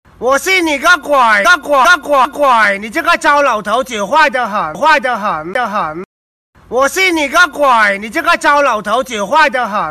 抖音我信你个鬼音效_人物音效音效配乐_免费素材下载_提案神器